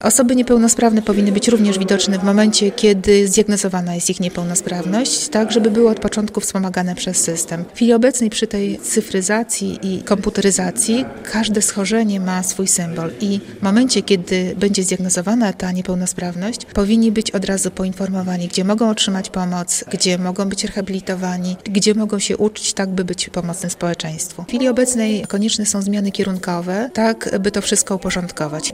Konieczne jest połączenie tego ze zdobyczami techniki tak, by służyło to przede wszystkim pacjentom – mówiła podczas konferencji w Biłgoraju, Anna Dąbrowska-Banaszek, kandydująca do Sejmu z list Prawa i Sprawiedliwości w okręgu chełmskim.